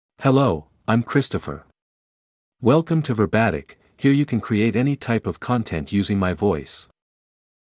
MaleEnglish (United States)
ChristopherMale English AI voice
Christopher is a male AI voice for English (United States).
Voice sample
Listen to Christopher's male English voice.
Christopher delivers clear pronunciation with authentic United States English intonation, making your content sound professionally produced.